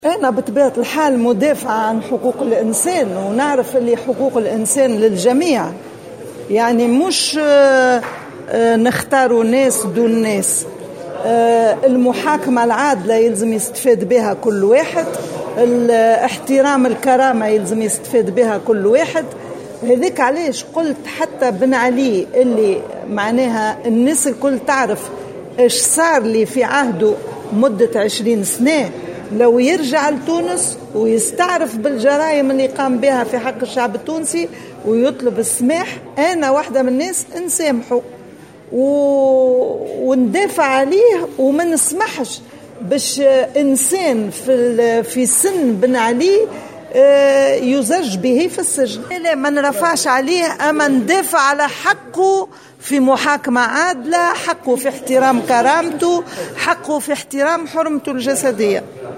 قالت رئيسة المنظمة التونسية لمناهضة التعذيب راضية النصراوي، في تصريح لمراسلة الجوهرة أف أم، إنها على استعداد للصفح عن الرئيس الأسبق زين العابدين بن علي، على رغم كل ما تعرضت له في فترة حكمه، شرط أن يعترف بما اقترفه من جرائم في حق الشعب التونسي ويعتذر عنها.
وأضافت النصراوي، لدى إشرافها اليوم السبت على ملتق جهوي حول علاقة الأمن بالمواطن 6 سنوات بعد الثورة، في مدنين، أنها ستساند بن علي وتدافع عن حقه في محاكمة عادلة ولن تسمح بالزج بشخص في سنّه في السجن، وفق تعبيرها.